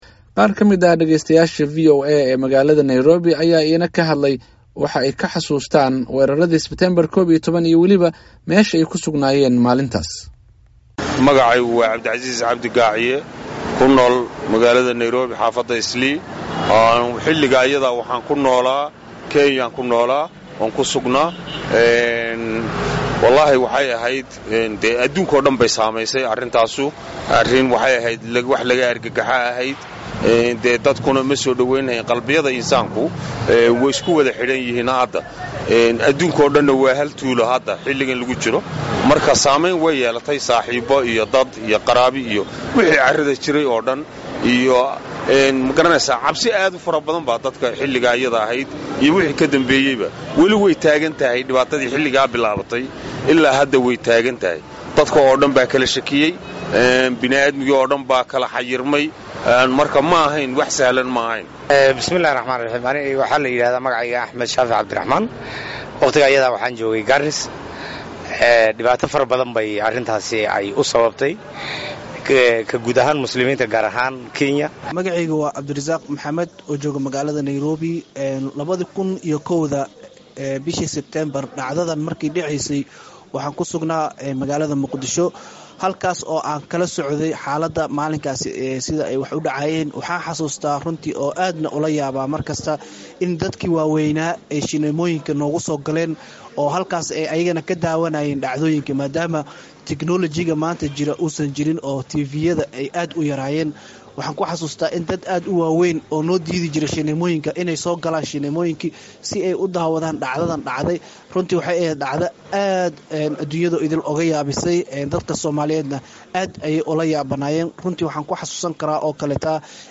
Dhageystayaasha VOA ee Nairobi ayaa ka hadlay waxay ka xasuustaan weerarradii September 11 iyo waliba Meesha ay ku sugnaayeen maalintaasi.